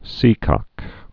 (sēkŏk)